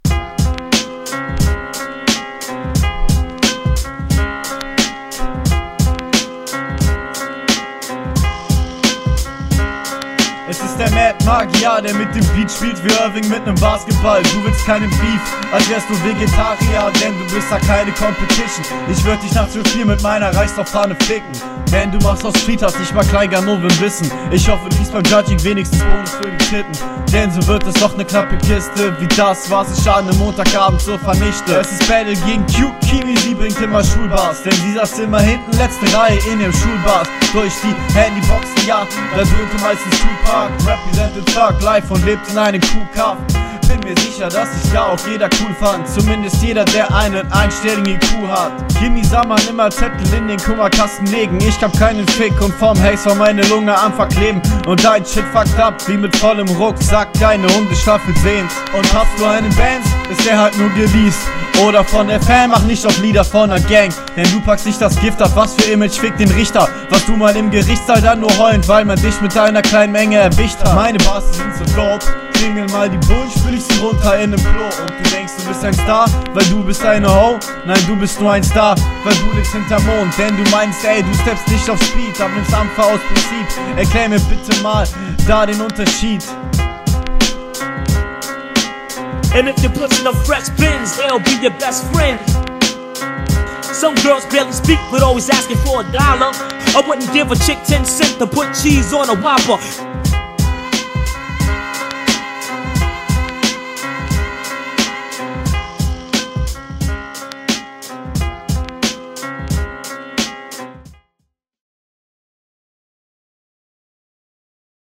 Flow: der Flow ist dope triffst den Takt, der Beat ist gut Text: Die Lines …
Flow: Flowlich sind paar gute Ansätze dabei jedoch teilweise schwer verständlich, misch die stimme nächste …